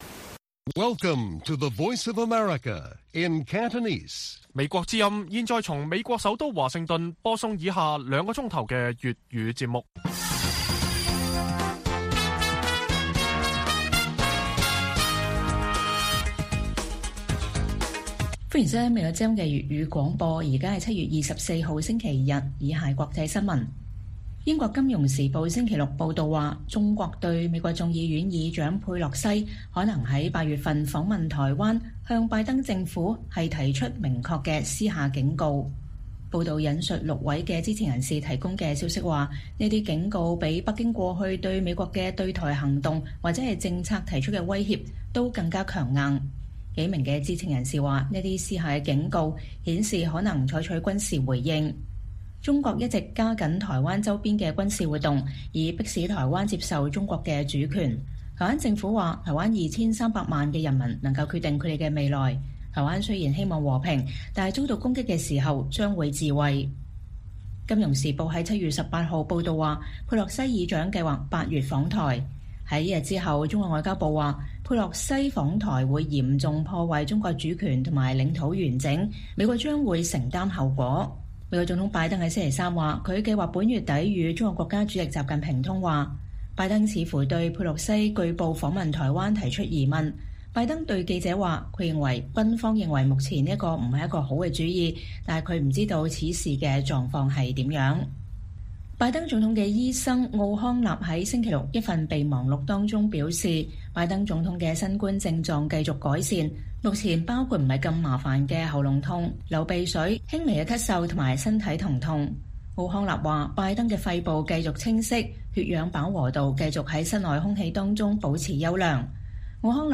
粵語新聞 晚上9-10點：報道：中國對美國眾院議長佩洛西訪問台灣提出強烈警告